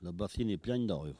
Localisation Saint-Jean-de-Monts
Langue Maraîchin
Catégorie Locution